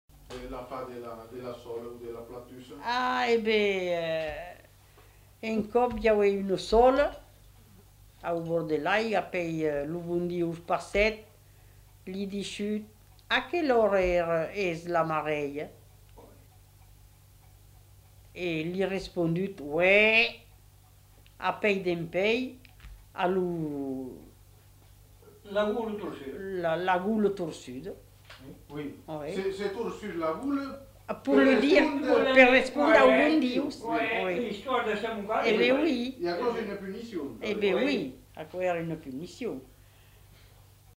Aire culturelle : Médoc
Genre : conte-légende-récit
Effectif : 1
Type de voix : voix de femme
Production du son : parlé